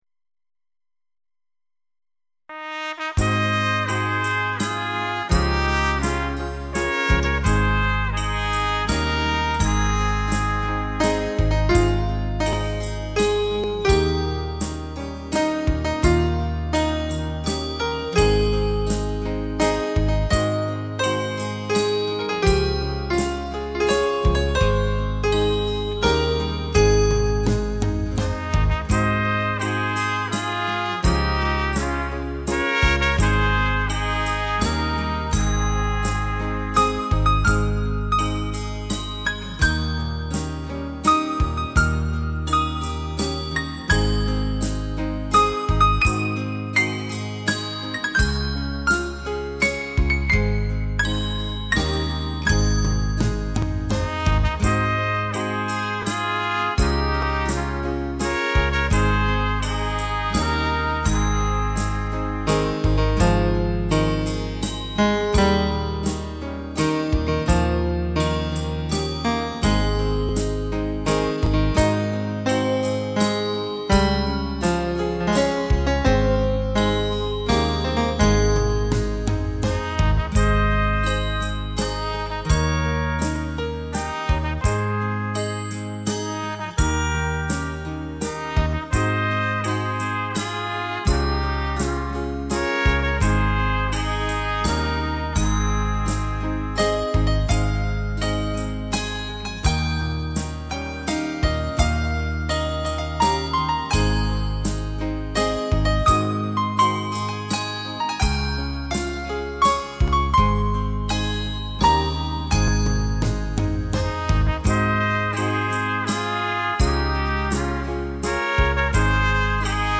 小提琴 钢琴演奏